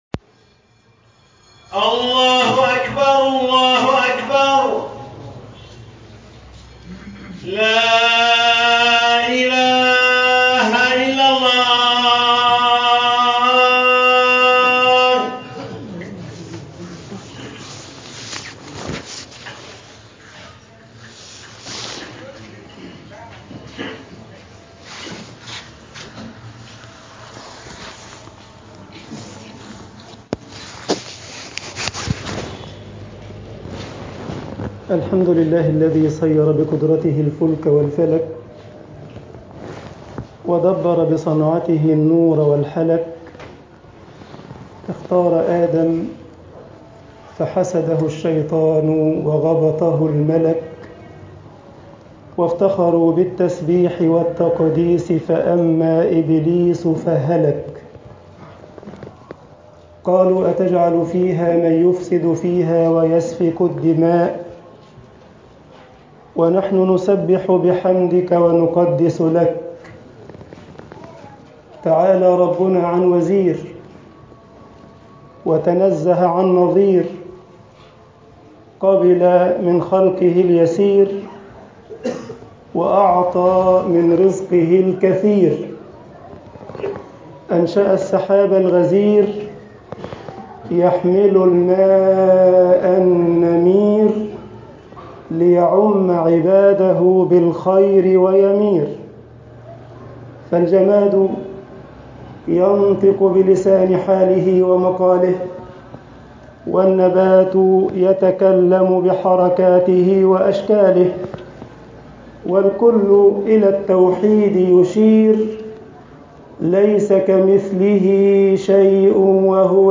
خطب الجمعة - مصر الظلم ظلمات والعدل رحمات طباعة البريد الإلكتروني التفاصيل كتب بواسطة